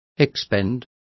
Complete with pronunciation of the translation of expend.